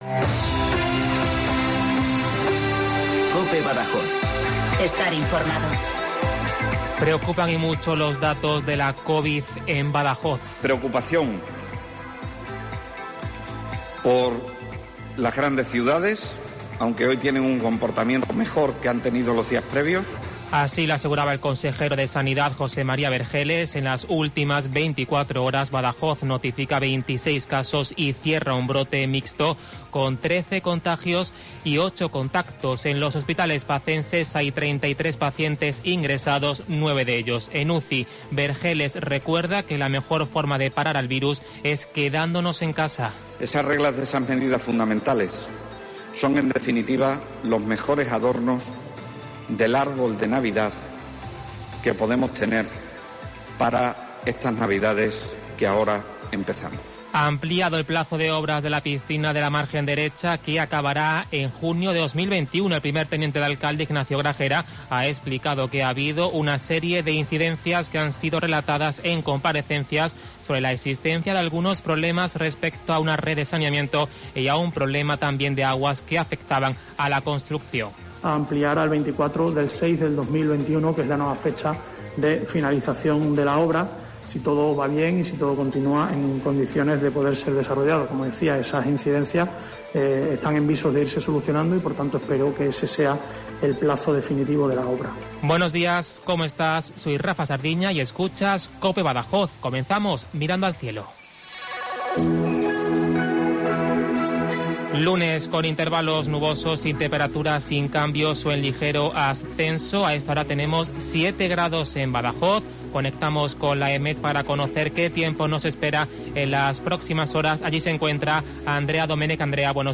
Informativo Badajoz - Lunes, 21 de diciembre de 2020